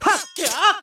File:Link voice sample MK8.oga
Link_voice_sample_MK8.oga.mp3